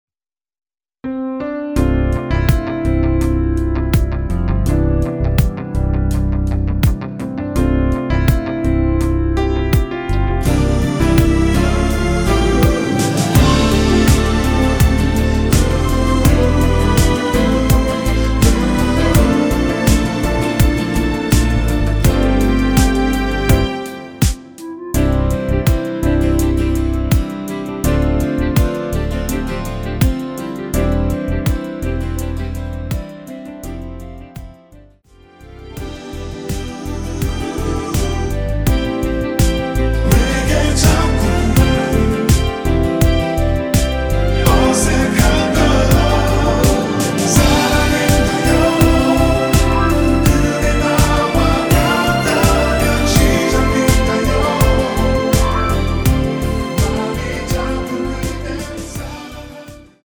원키에서(-3)내린 (1절+후렴) 멜로디와 코러스 포함된 MR입니다.(미리듣기 확인)
앞부분30초, 뒷부분30초씩 편집해서 올려 드리고 있습니다.